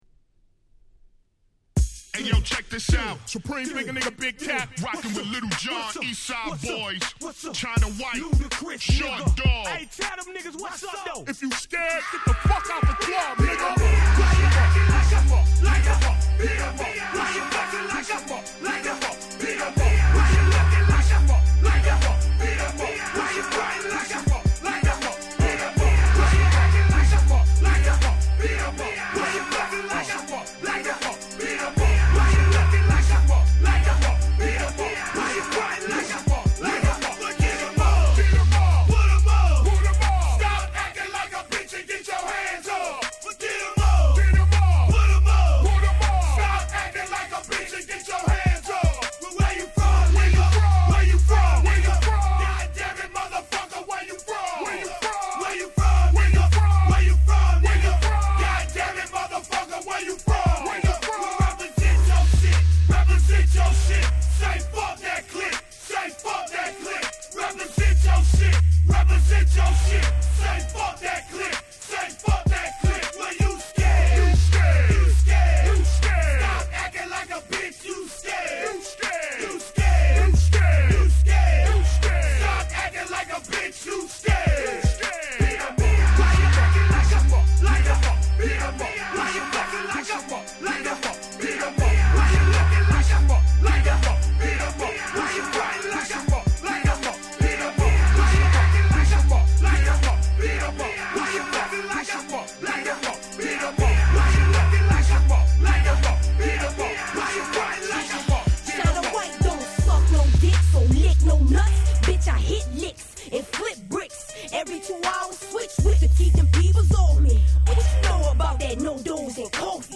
01' Dirty South Classics !!
South系Hip Hopがまだ大爆発する前の超絶クラシックナンバー！！
イケイケでめちゃくちゃ格好良いです！